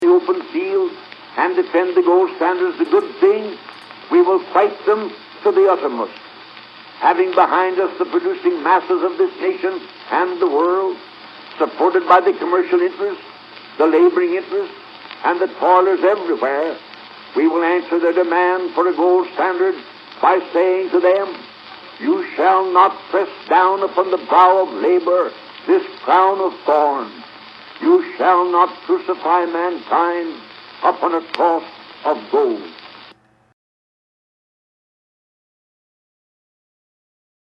On July 9, 1896 at the Democratic National Convention William Jennings Bryan delivered his famous
'cross of gold' speech (MP3) which closed with: